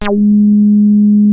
WEST HARD#G3.wav